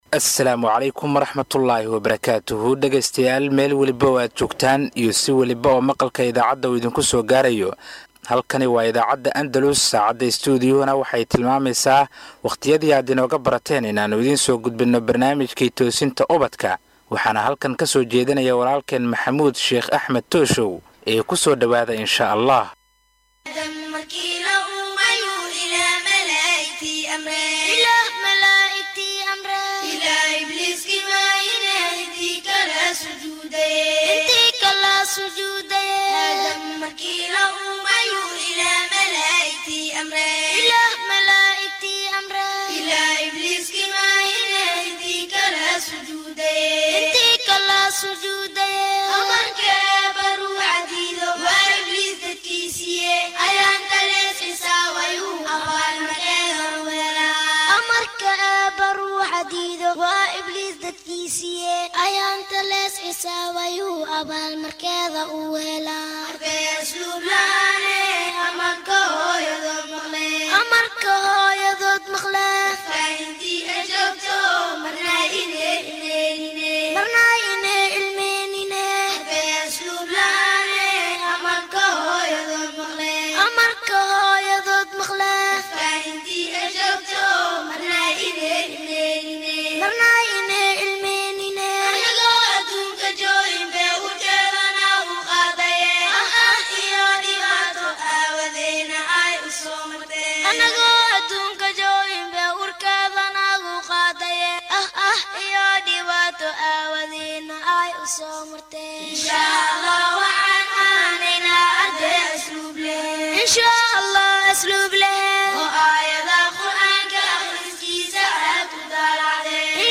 Halkan waxad ka dhagaysan kartaa barnaamijka todobaadlaha ah ee Toosinta Ubadka kaasi oo ka baxa idaacadda Andalus, barnaamijkan oo ah barnaamij ay caruurtu aad u xiisayso wuxuu ka koobanyahay dhowr xubnood oo kala ah wicitaanka iyo bandhiga caruurta, jawaabta Jimcaha, Iftiiminta qalbiga iyo xubinta su aasha toddobaadka.